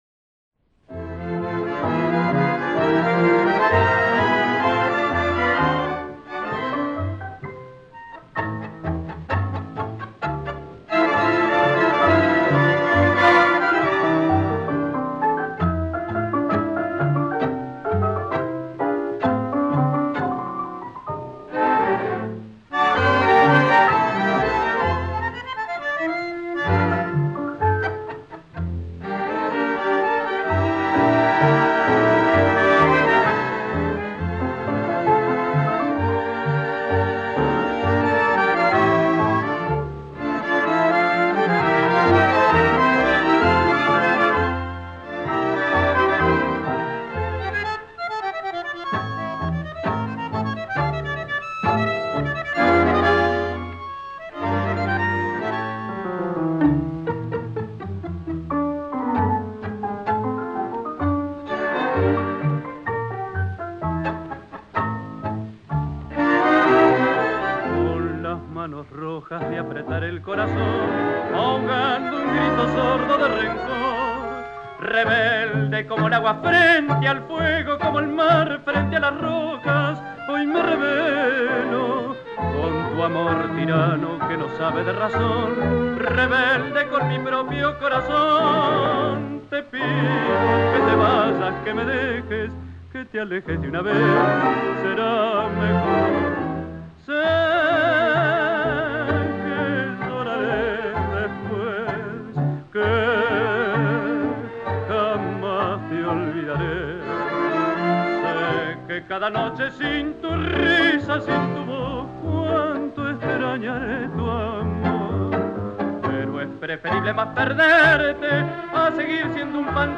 Dreamy….